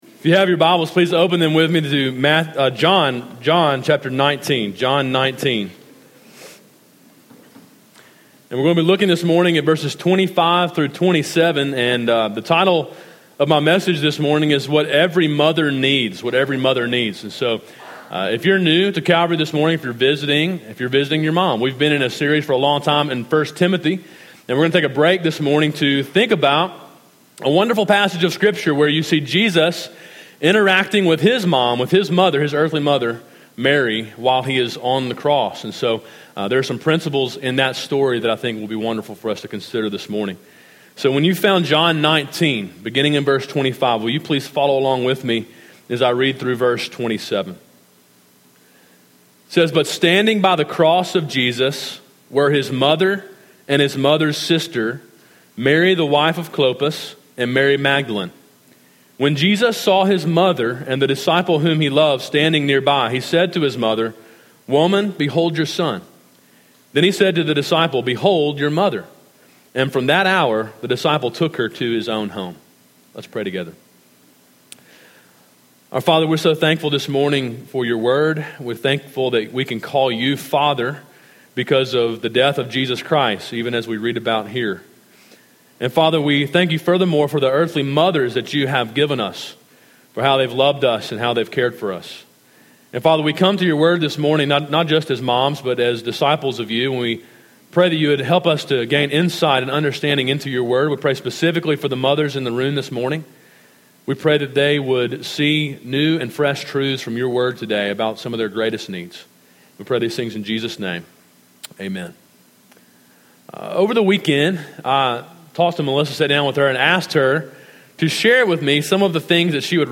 A sermon preached on Mother’s Day, May 8, 2016.